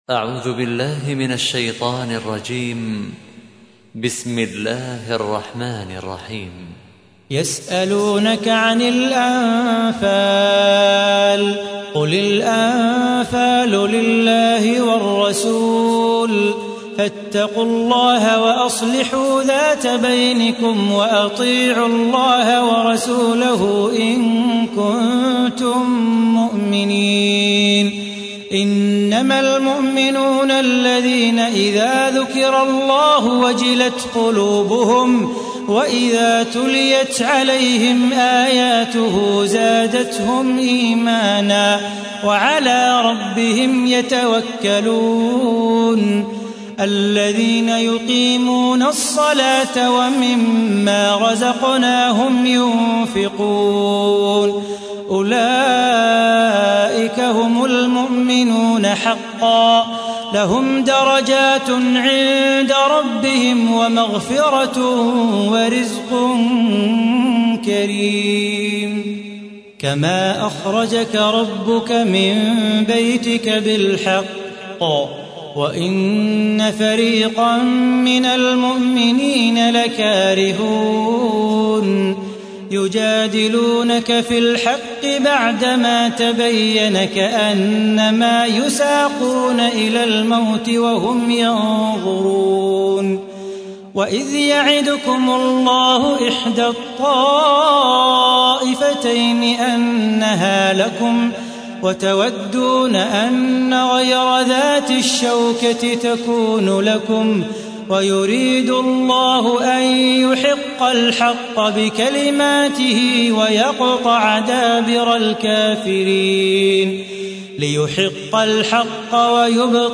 تحميل : 8. سورة الأنفال / القارئ صلاح بو خاطر / القرآن الكريم / موقع يا حسين